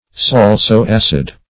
Search Result for " salso-acid" : The Collaborative International Dictionary of English v.0.48: Salso-acid \Sal"so-ac`id\, a. [L. salsus salted, salt + acidus acid.] Having a taste compounded of saltness and acidity; both salt and acid.